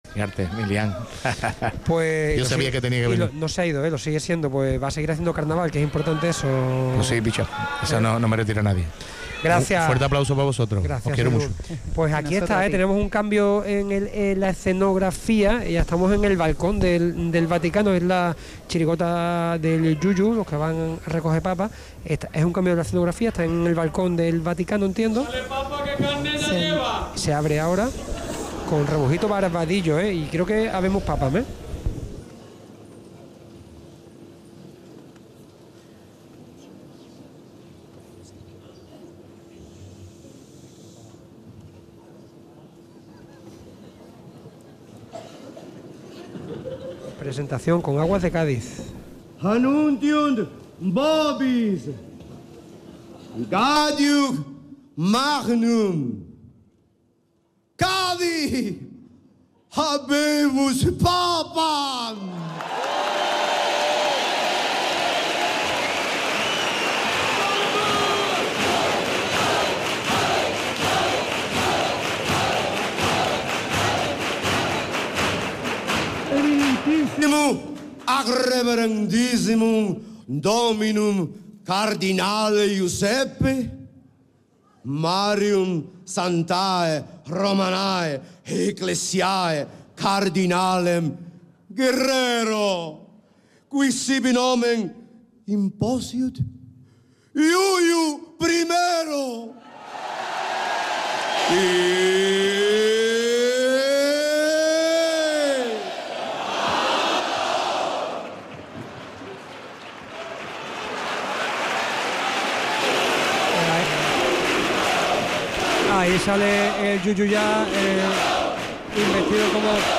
Audio de La cantera del Carnaval de Cádiz en Podium Podcast
Chirigota - Los que van a coger papas Final